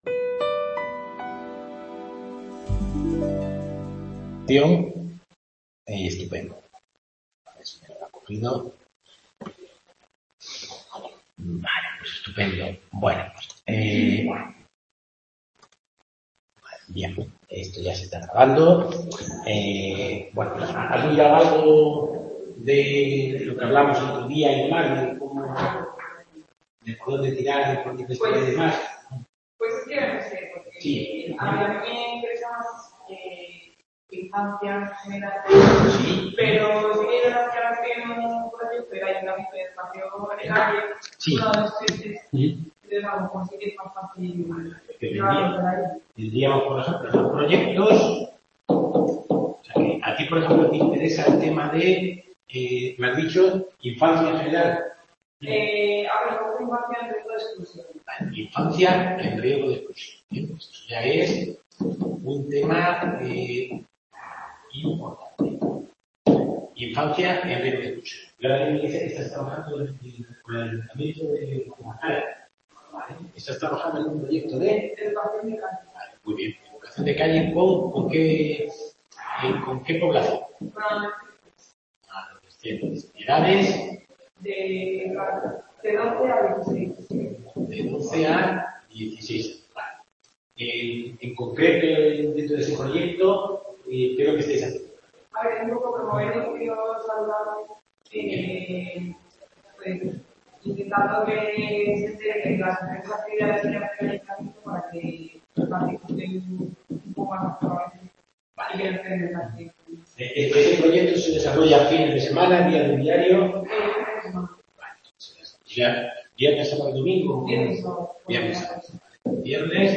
Tutoría Métodos de Investigación en Educación Social